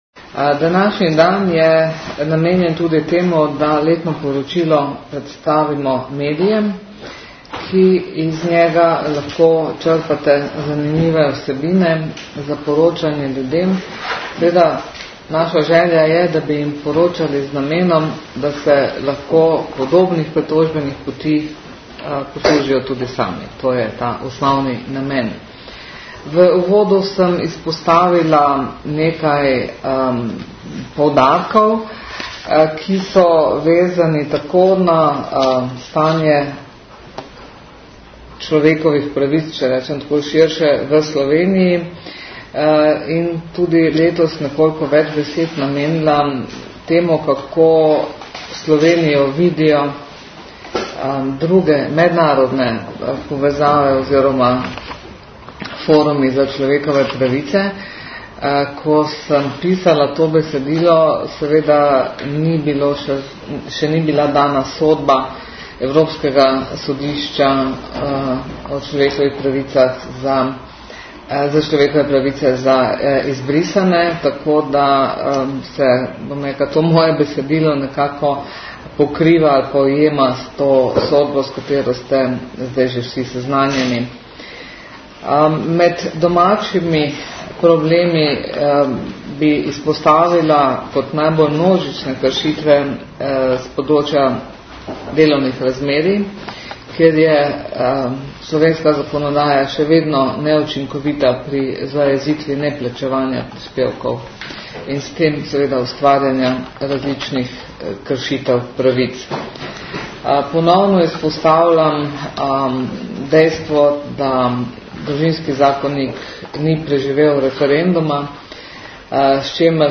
Po predaji je skupaj z namestnico in namestnikoma na tiskovni konferenci v prostorih Varuha na Dunajski 56 predstavila temeljne poudarke iz poročila ter odgovarjala na vprašanja, povezana z vsebino dela Varuha ter aktualnimi vprašanji varovanja človekovih pravic. Zvočni posnetek tiskovne konference v prostorih Varuha vir slike varuhinje s predsednikom DZ RS: spletni portal Državnega zbora RS